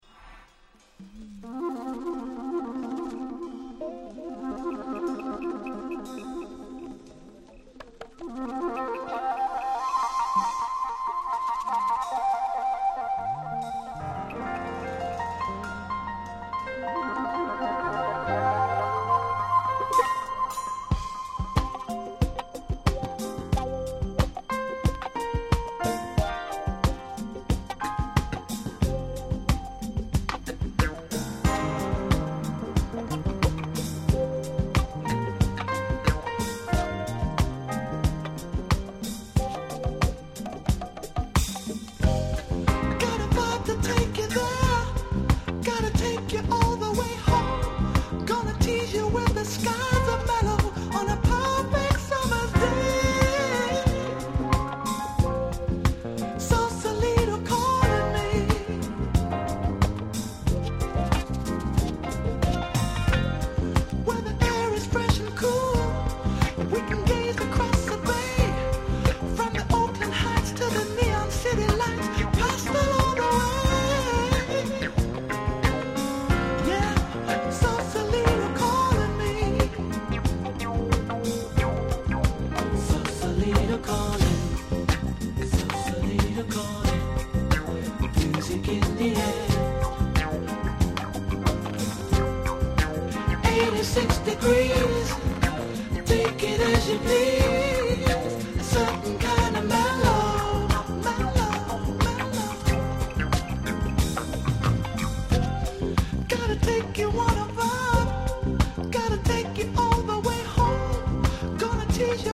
99' Nice UK Soul LP !!